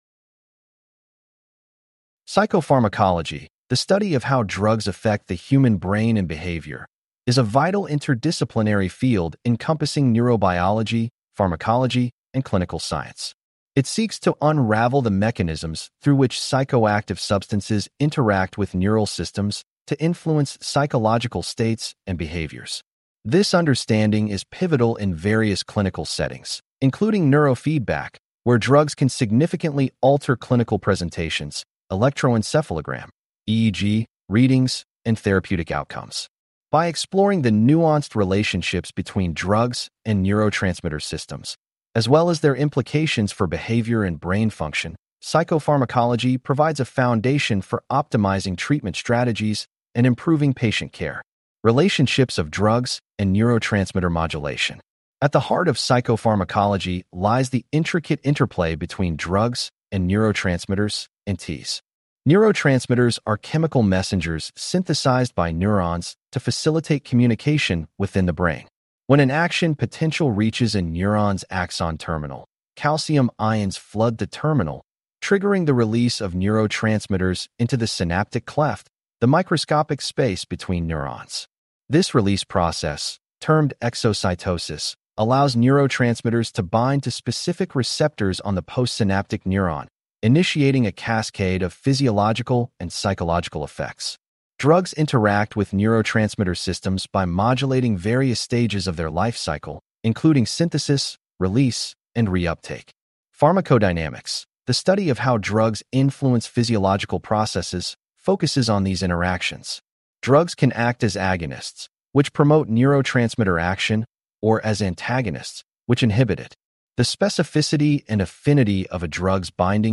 Please click on the podcast icon below to hear a lecture over this unit.